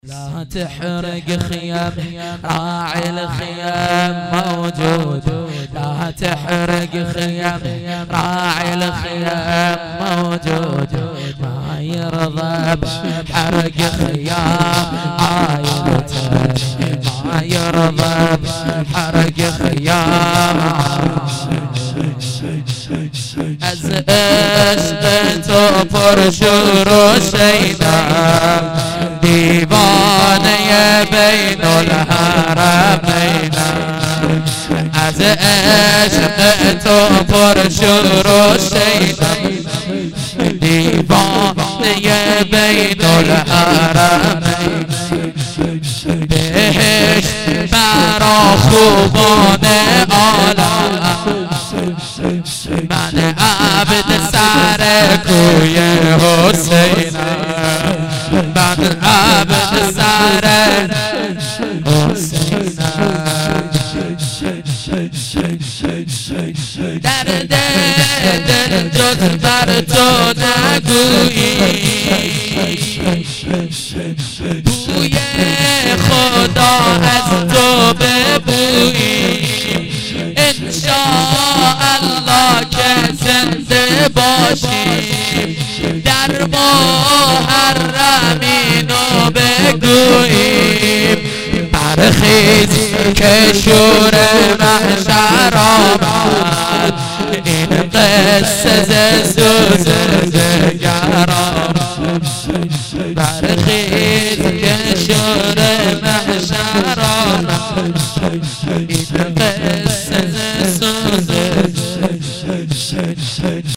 shadat-piambar-v-emam-hasan-92-shor-arabi-farsi.mp3